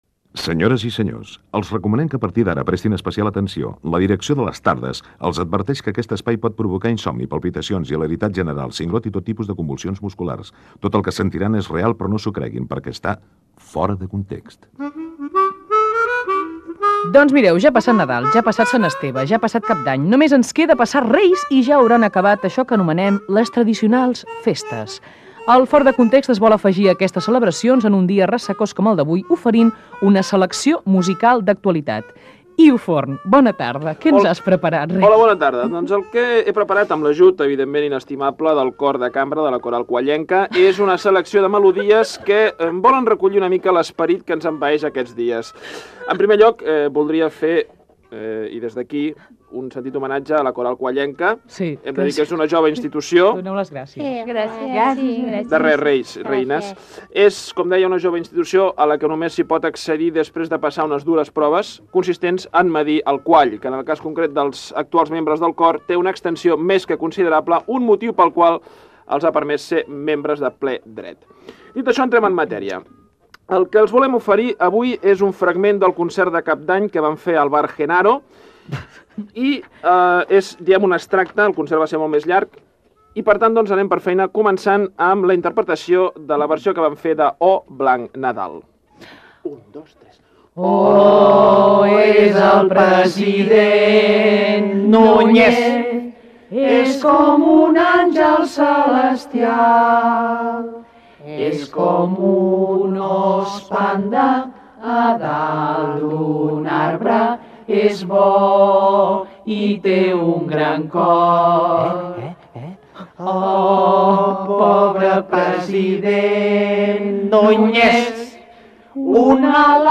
Nadales iròniques i relacionades amb l'actualitat cantades per l'equip del programa.
Entreteniment